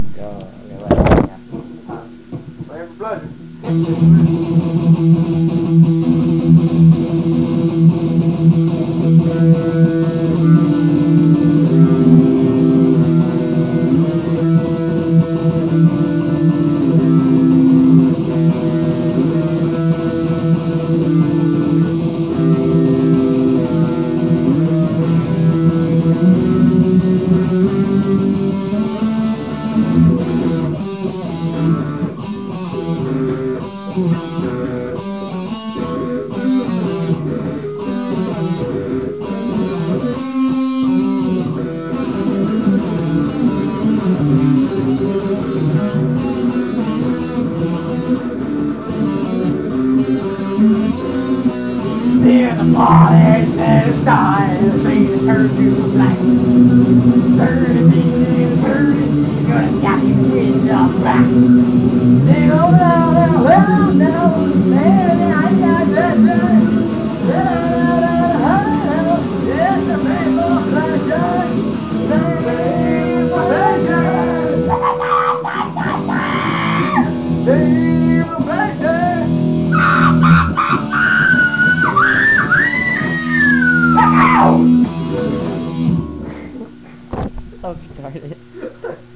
2 guys tryina have fun (really old)